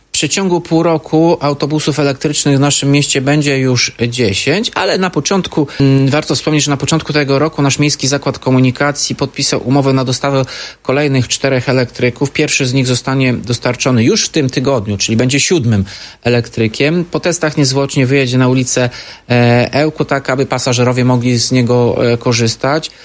Powiedział Tomasz Andrukiewicz, prezydent Ełku.